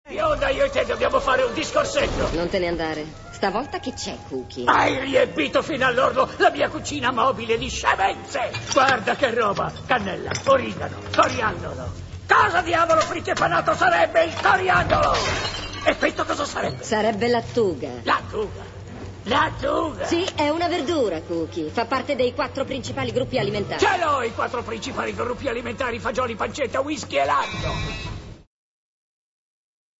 dal film d'animazione "Atlantis - L'impero perduto", in cui doppia Cookie.